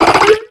Cri de Méditikka dans Pokémon X et Y.